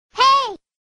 веселые
заводные
женский голос
Флейта
Классная веселая смс-ка